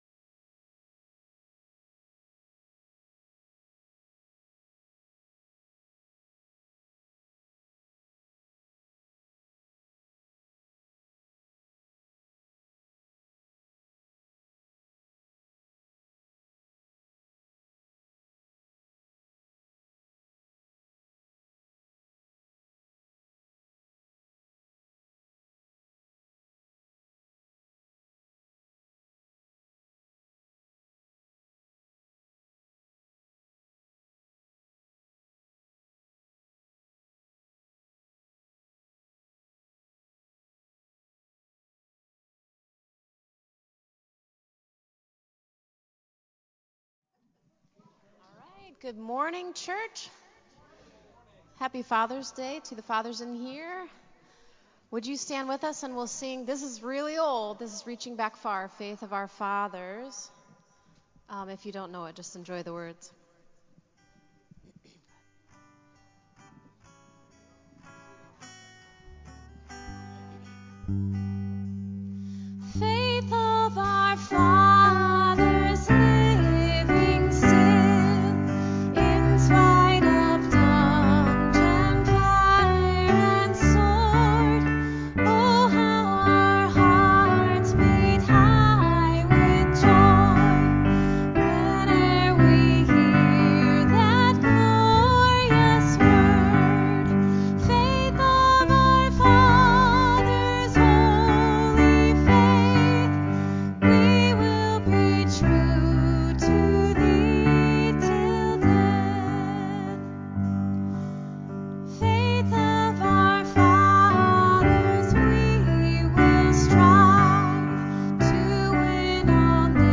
Praise Songs from June 19 2022